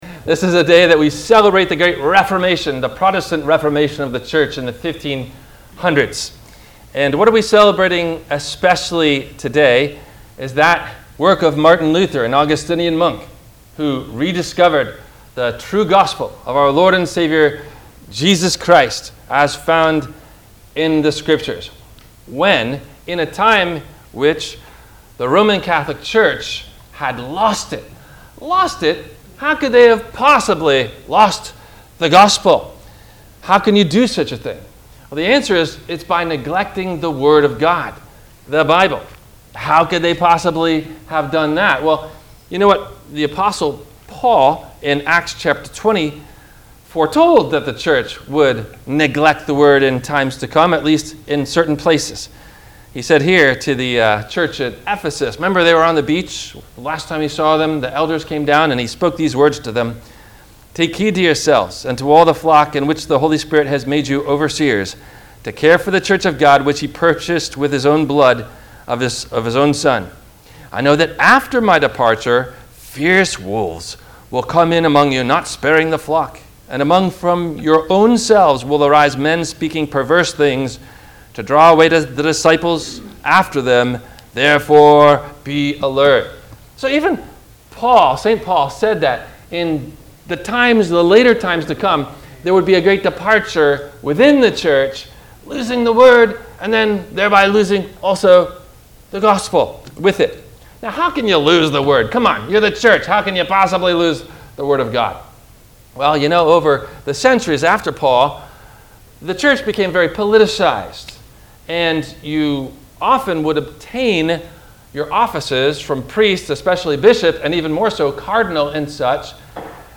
What About The Reformation and Romans 3? – WMIE Radio Sermon – November 10 2025